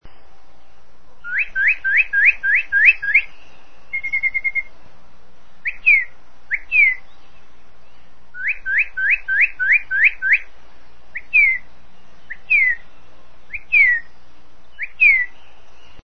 Descarga de Sonidos mp3 Gratis: pajaro 14.
PajarosBIRD4.mp3